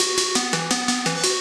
Index of /musicradar/shimmer-and-sparkle-samples/170bpm
SaS_Arp01_170-E.wav